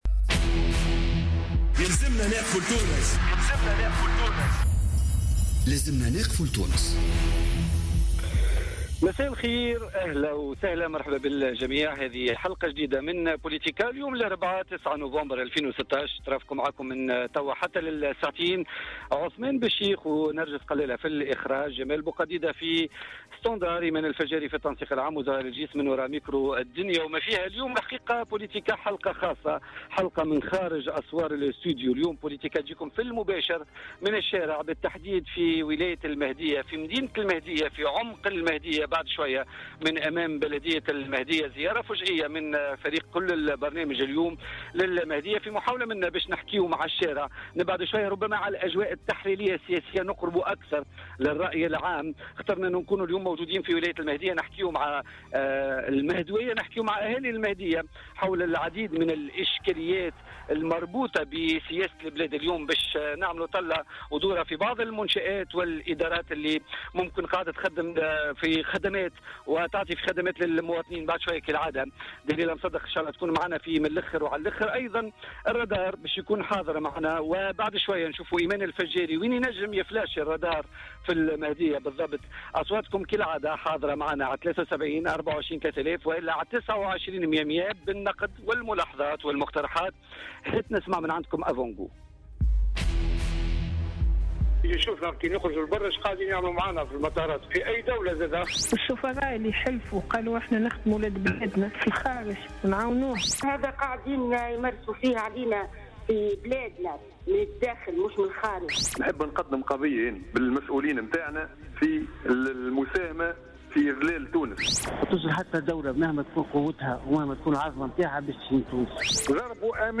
حلقة خاصة من بوليتيكا في ولاية المهدية